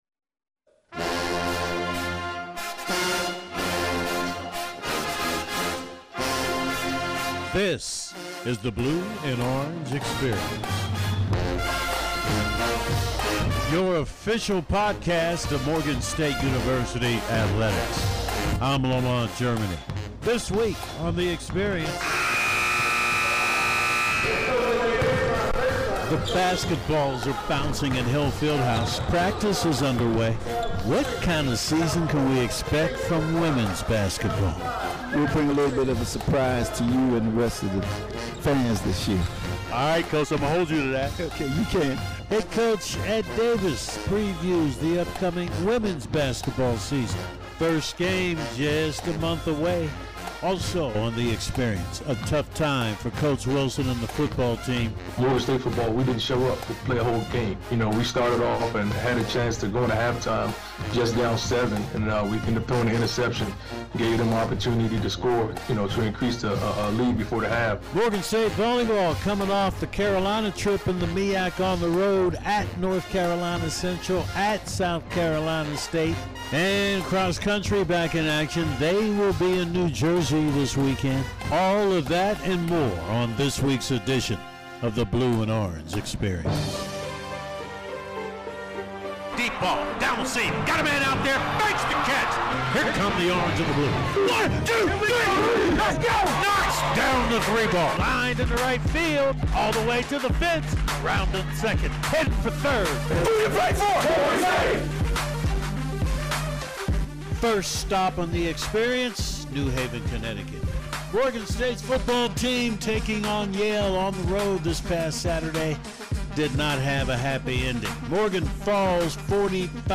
features an interview